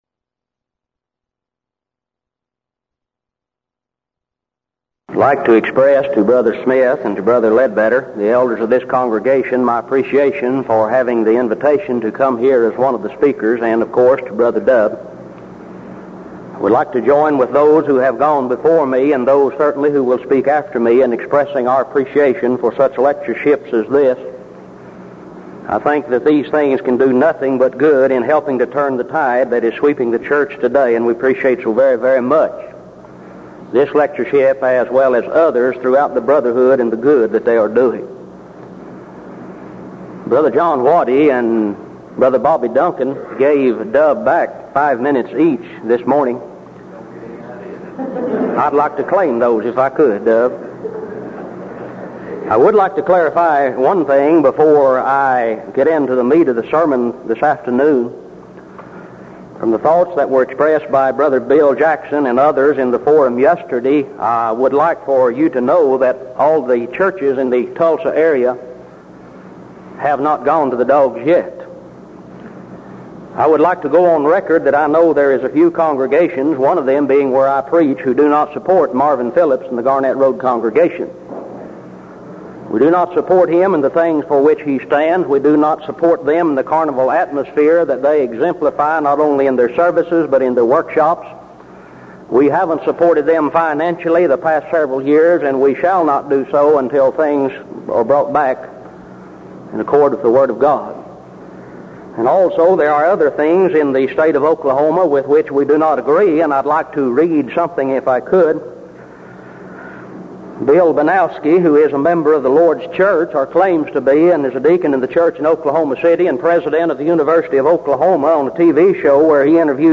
Event: 1983 Denton Lectures
lecture